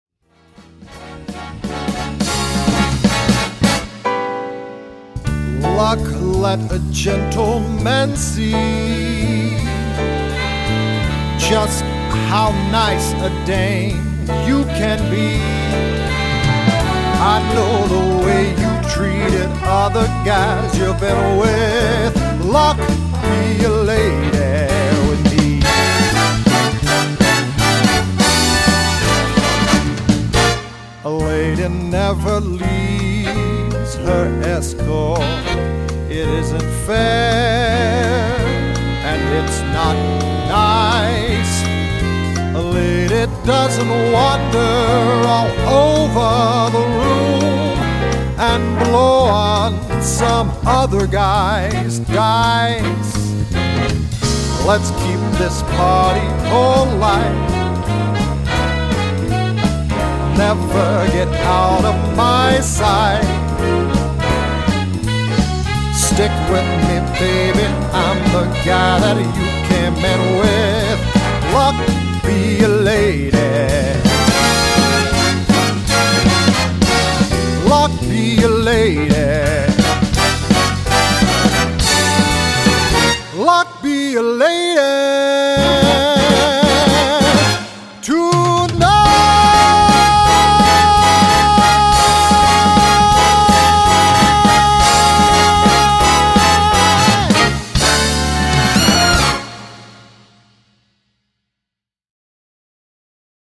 his blue-eyed soul voice is convincing